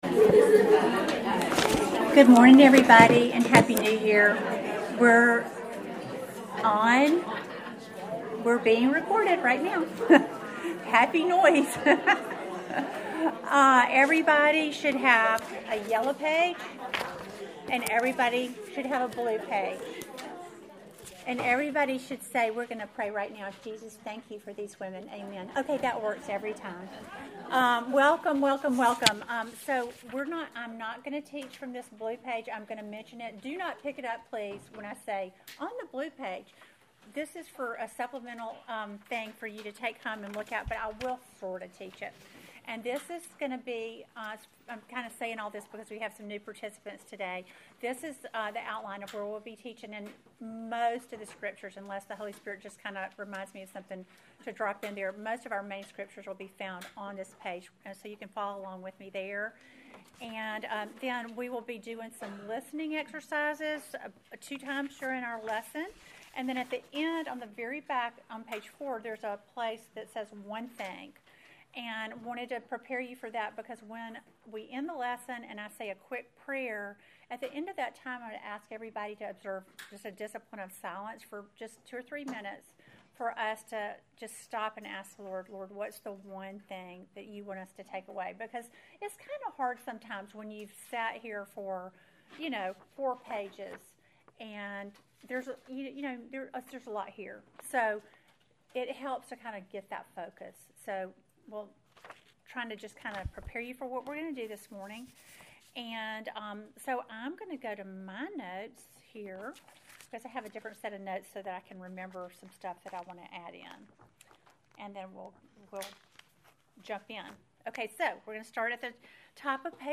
Welcome to the ninth lesson in our series WAITING ON GOD!